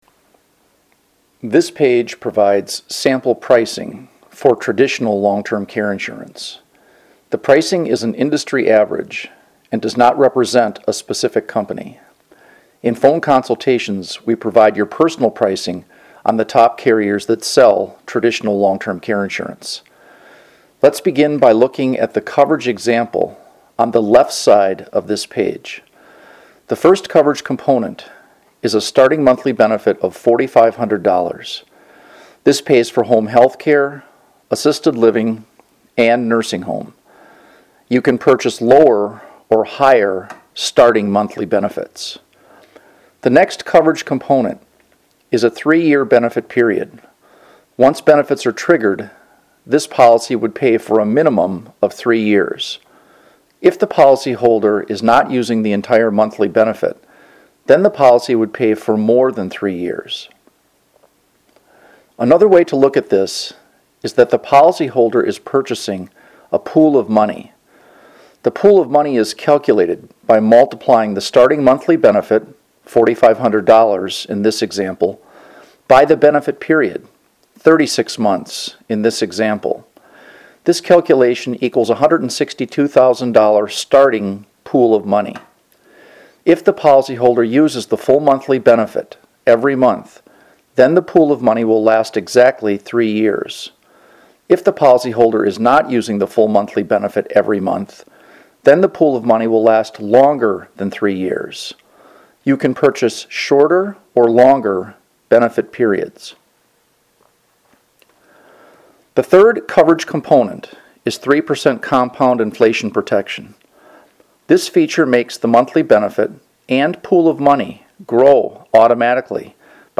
Listen to a narrated guide for the chart above